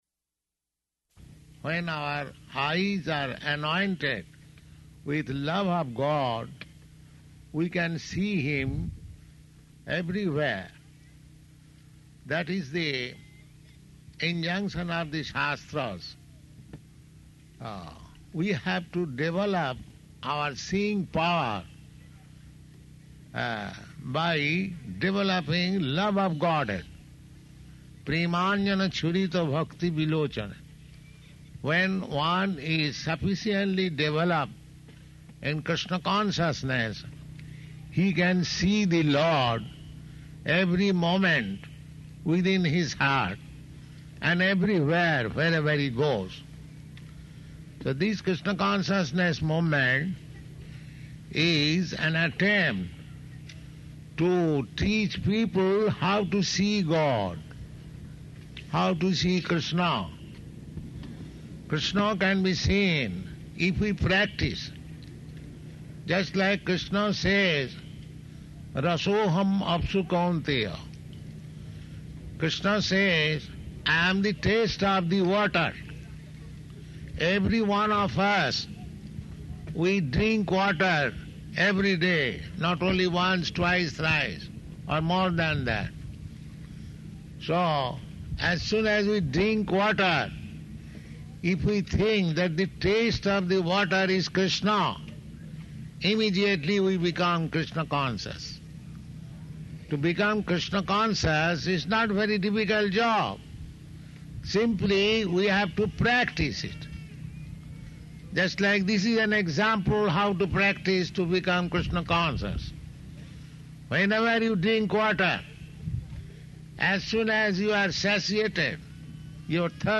Ratha-yātrā Address
Type: Lectures and Addresses
Location: San Francisco